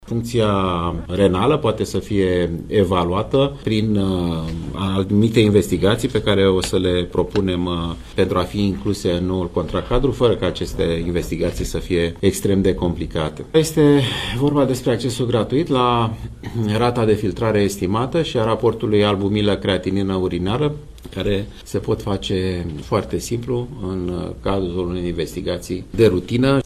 Singura analiză decontată acum este legată de creatinina serică, dar aceasta nu reflectă suficient modul în care o posibilă boală de rinichi evoluează, spune ministrul Alexandru Rafila.